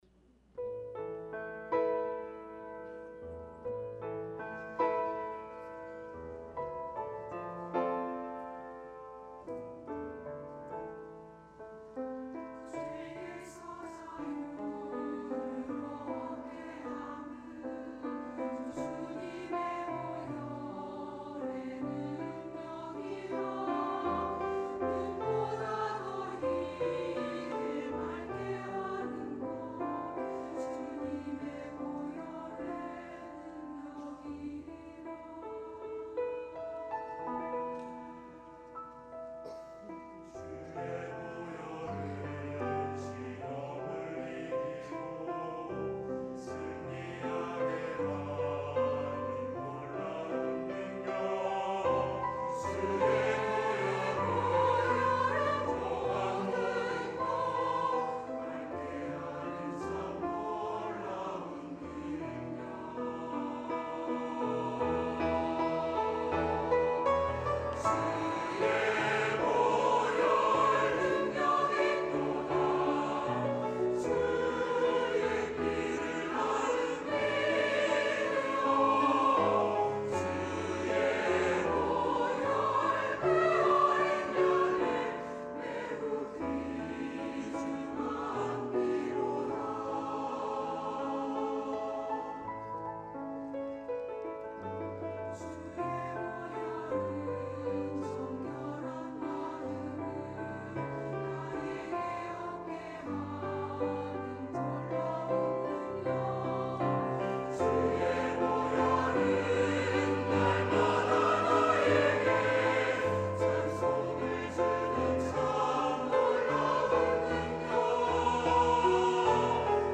찬양 :: 주의 보혈